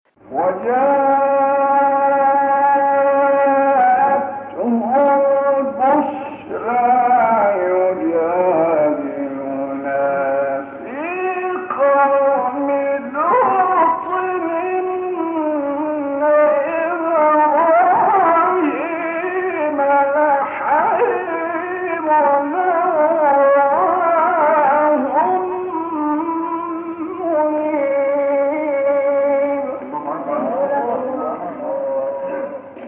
گروه شبکه اجتماعی: فرازهای صوتی از تلاوت قاریان به‌نام مصری را می‌شنوید.